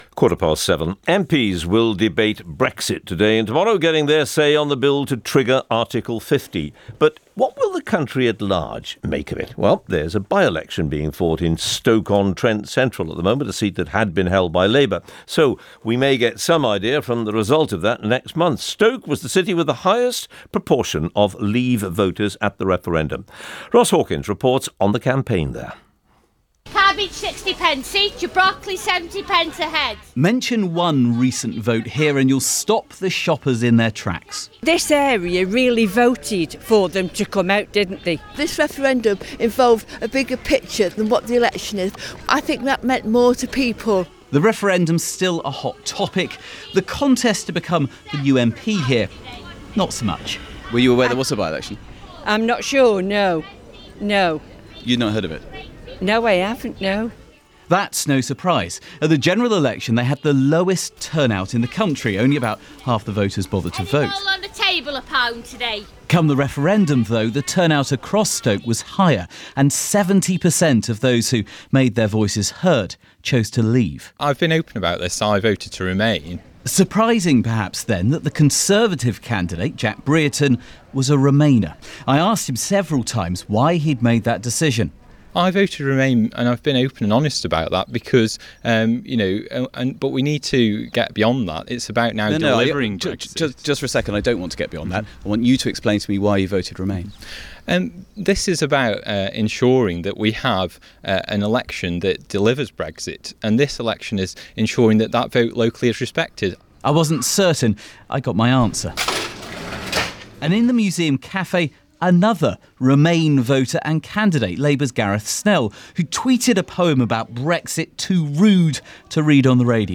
He did not give a answer to the question of why he voted to remain in the EU referendum.